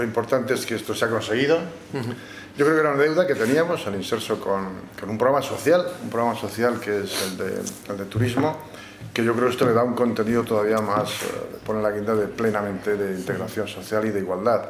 señaló al concluir el acto el director general del IMSERSO, César Antón (archivo MP3).